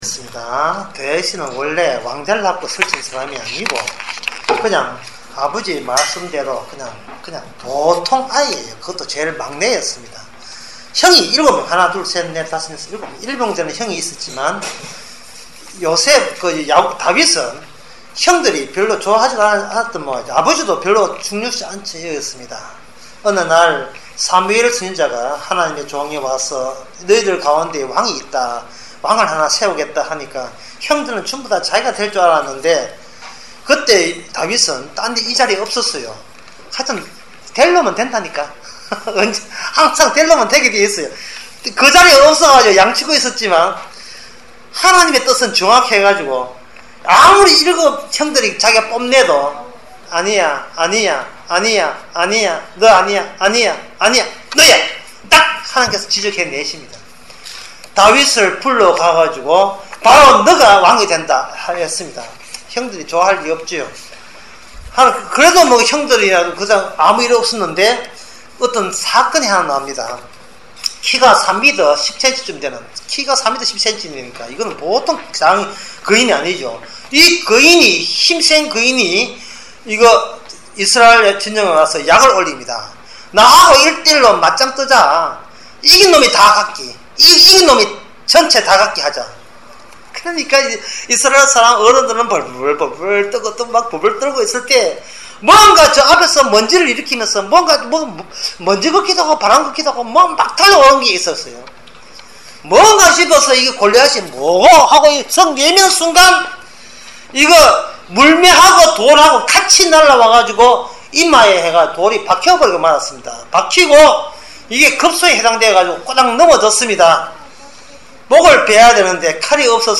주일학교 설교